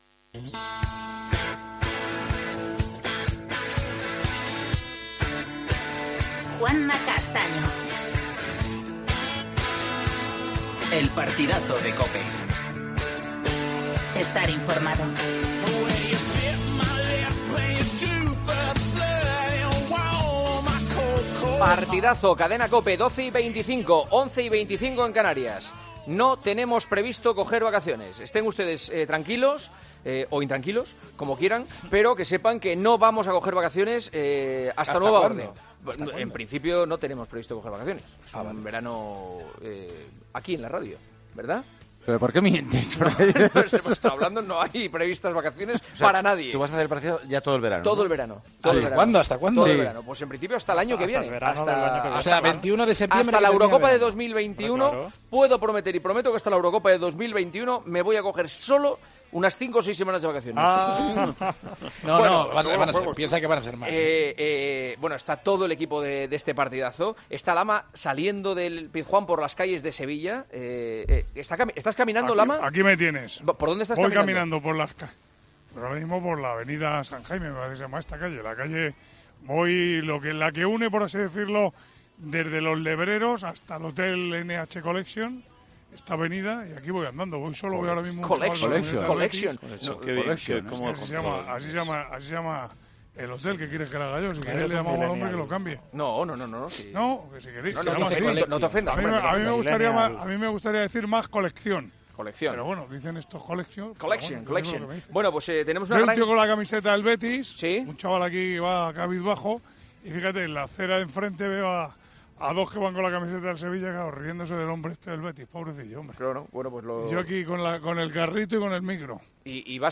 AUDIO: Debatimos en El Partidazo de COPE la victoria del Sevilla ante el Betis (2-0) en el primer partido de LaLiga tras el parón por la pandemia.